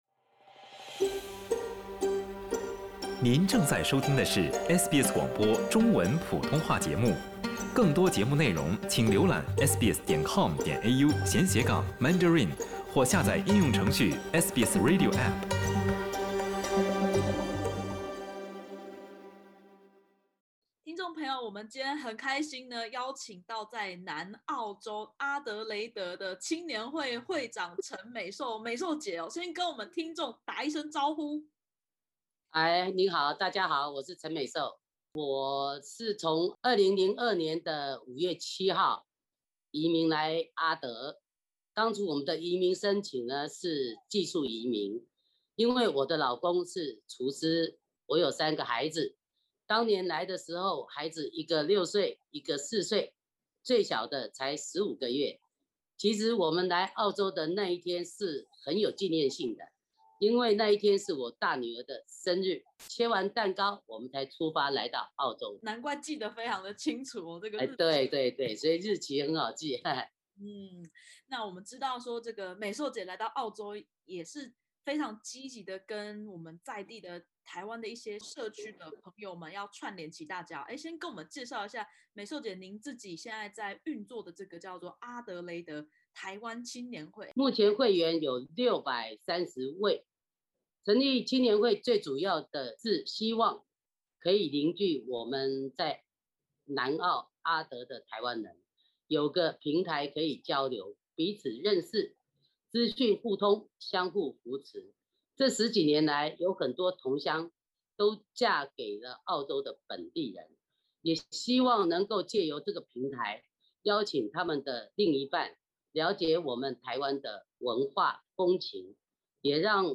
在南澳州阿德莱德，有一群台湾社区成员，因熟悉的家乡味而凝聚。点击首图收听采访音频。